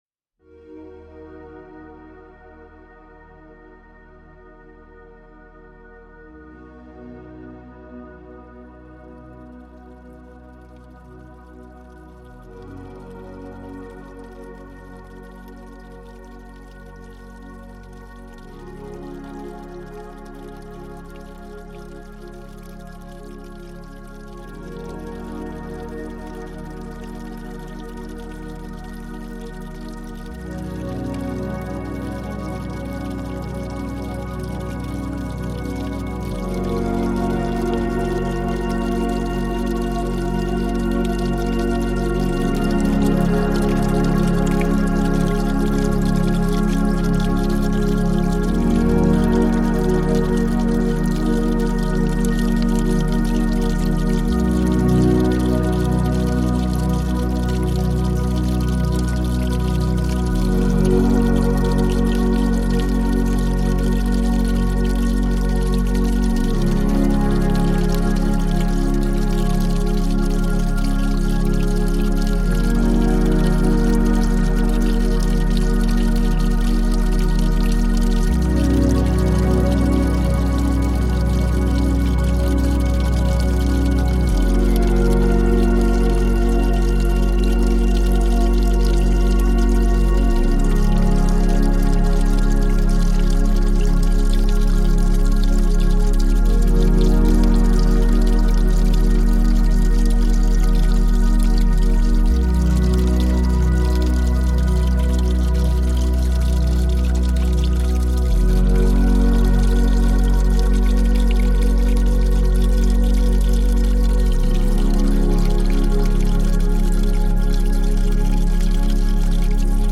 water sounds reimagined